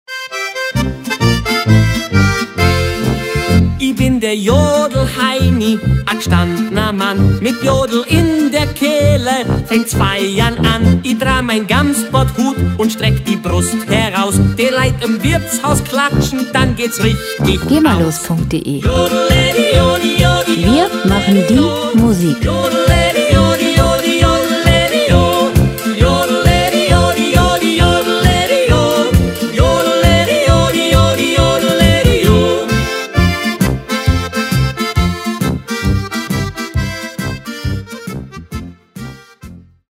Schlager Pop Musik - Aprés Ski
Musikstil: Bayerische Polka
Tempo: 140 bpm
Tonart: F-Dur
Charakter: amüsant, heiter